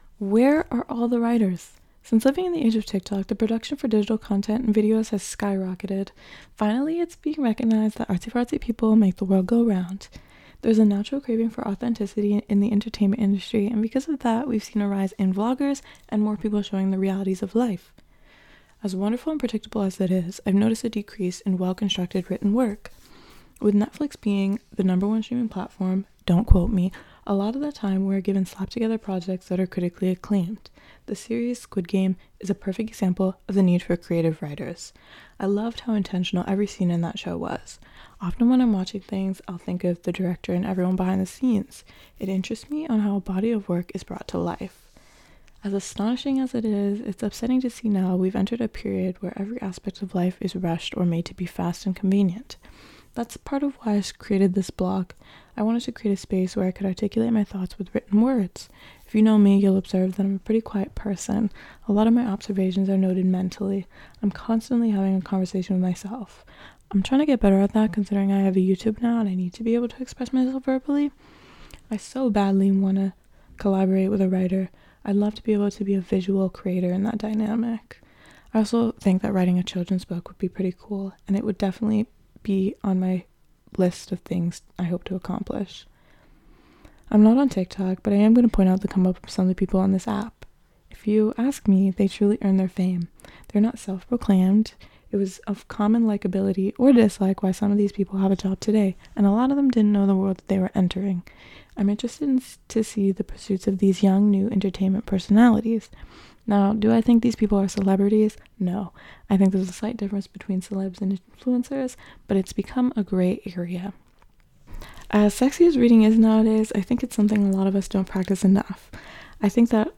LISTEN TO ME READ IT BELOW